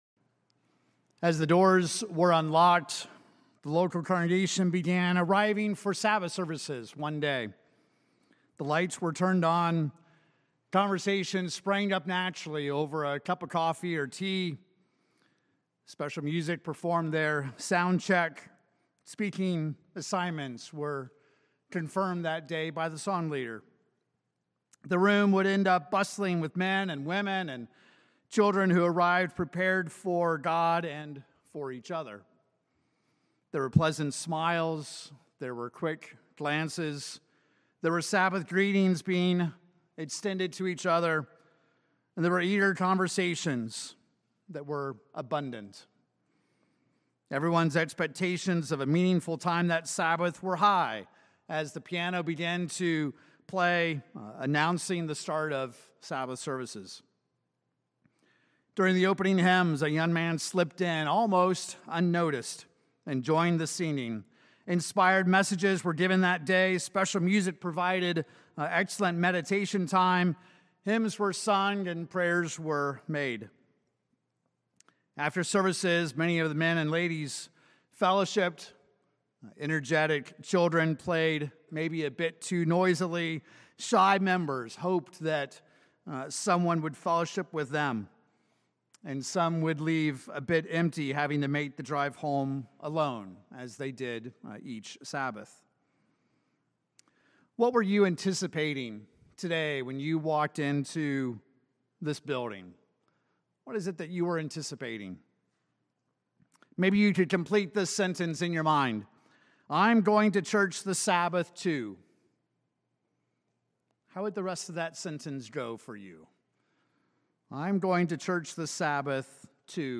This sermon examines the two ultimate questions as you consider your personal expectations for the Sabbath.